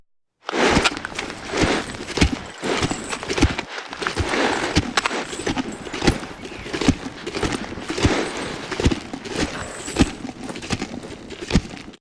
sfx_rodeo_foley_mix.opus